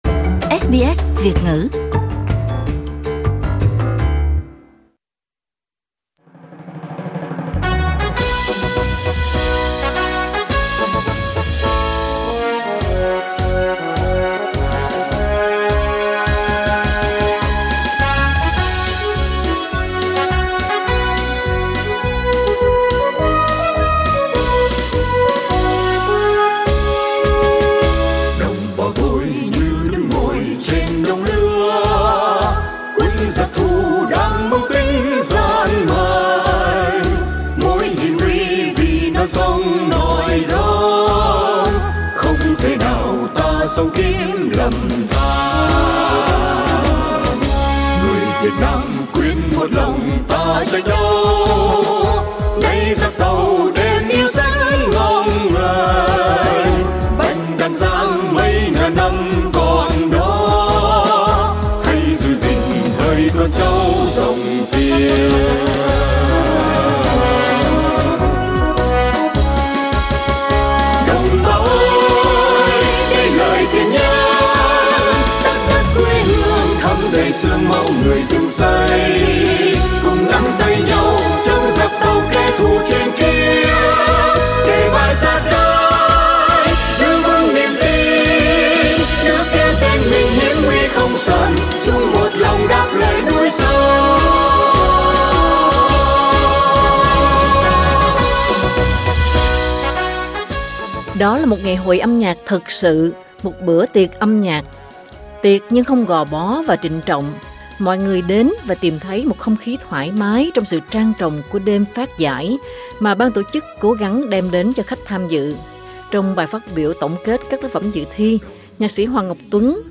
Đêm phát giả Viet Song Contest 2018 tại Sydney Source: SBS